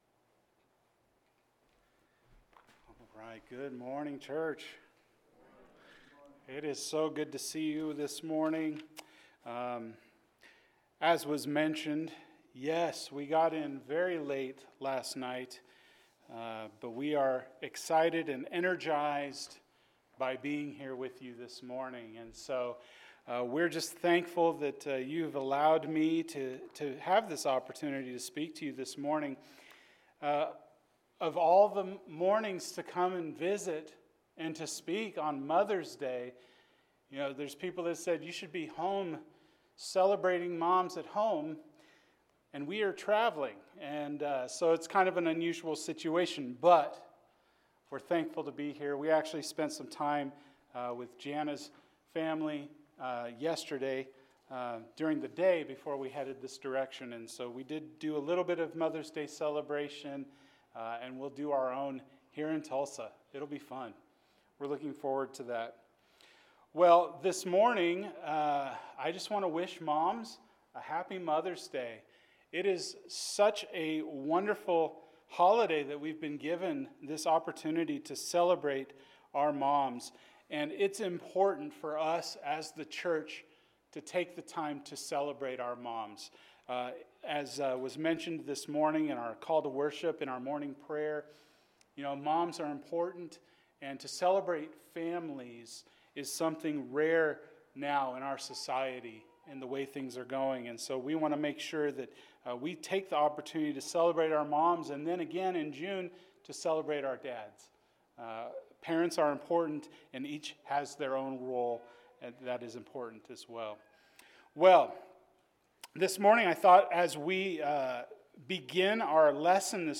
Moms Are the Best! – Luke 7:11-17 – Sermon